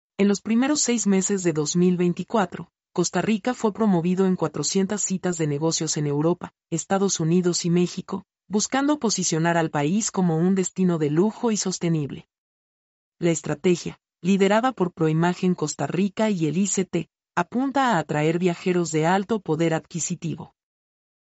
mp3-output-ttsfreedotcom-64-1.mp3